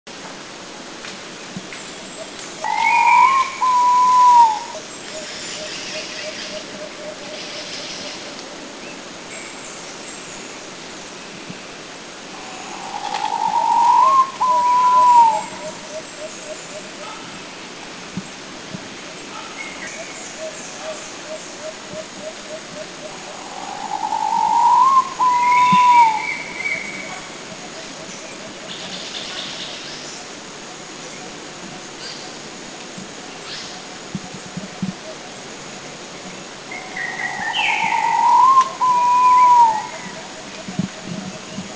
Red-bellied Pitta
Pitta erythrogaster
Red-belliedPitta.mp3